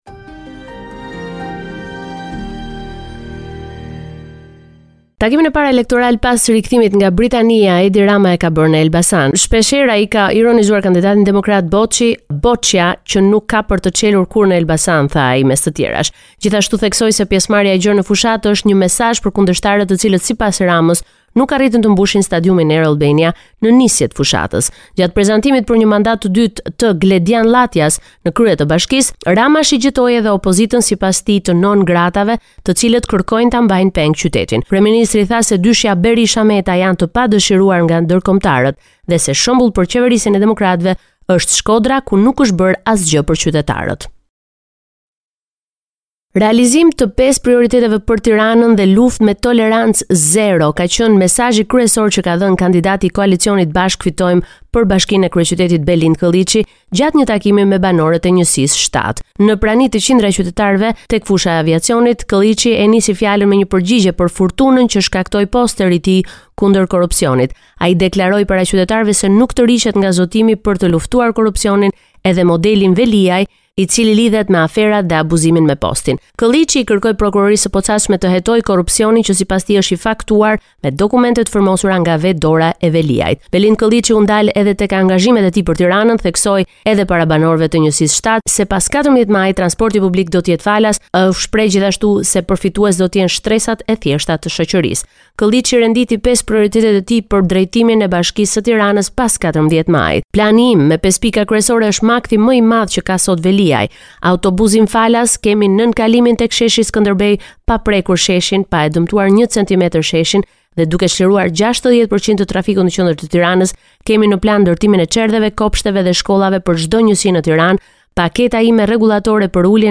Raporti me të rejat më të fundit nga Shqipëria.